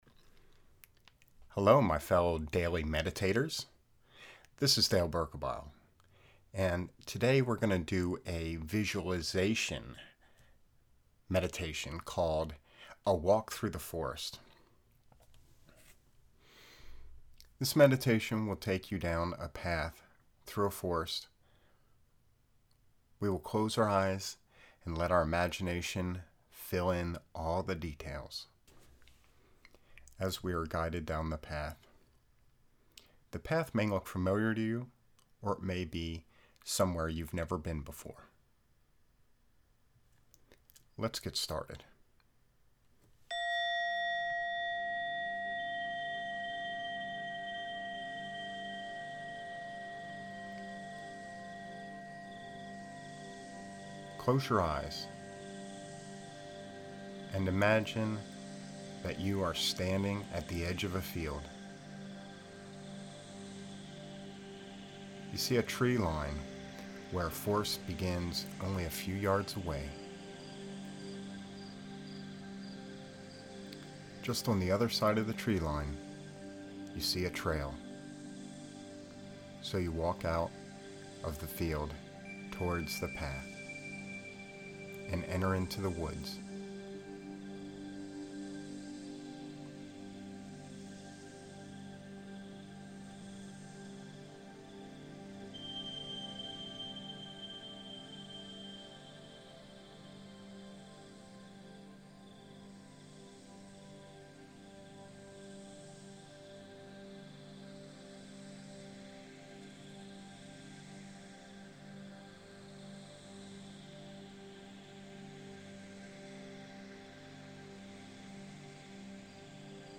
A Walk Through The Forrest – visualization meditation
A-Walk-Through-The-Forrest-visualization-meditation-_mixdown.mp3